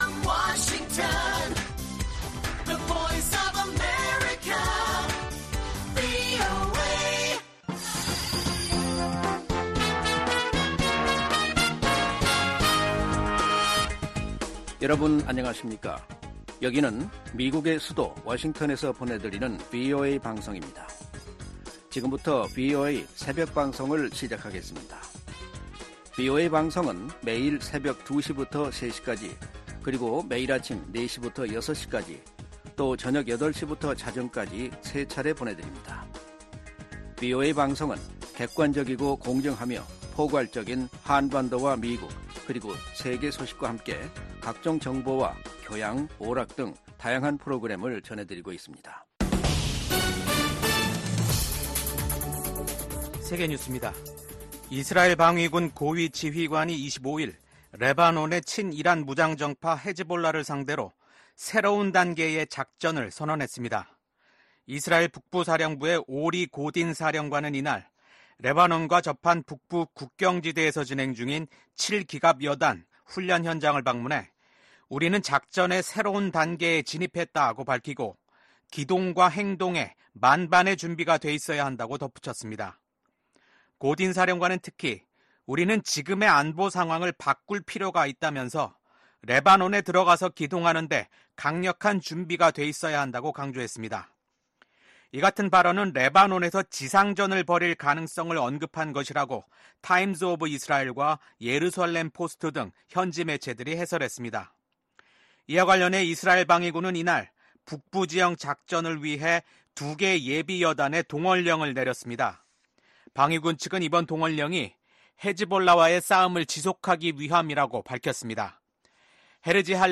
VOA 한국어 '출발 뉴스 쇼', 2024년 9월 26일 방송입니다. 조 바이든 미국 대통령이 임기 마지막 유엔총회 연설에서 각국이 단합해 전 세계가 직면한 어려움들을 이겨내야 한다고 밝혔습니다. 토니 블링컨 미국 국무장관은 심화되는 북러 군사협력을 강하게 규탄하며 국제사회의 강력한 대응의 필요성을 역설했습니다.